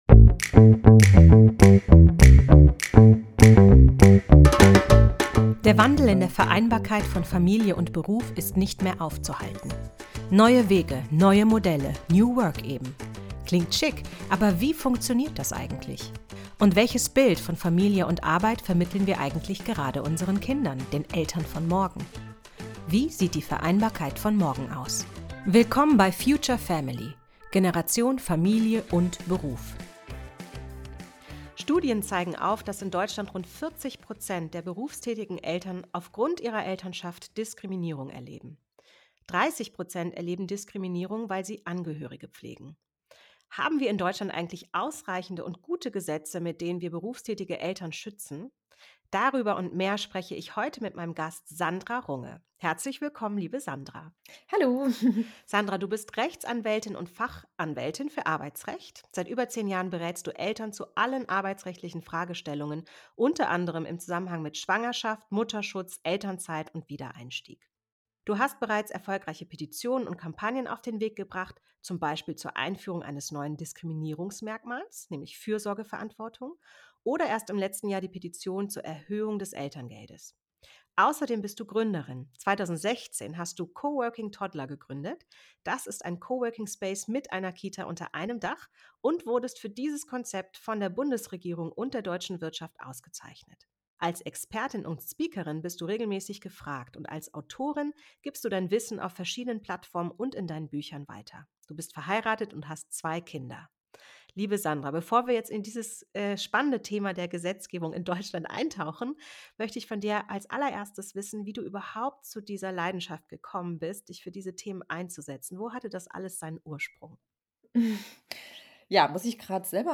Freut euch auf ein spannendes Gespräch, das Mut macht und zum Nachdenken anregt.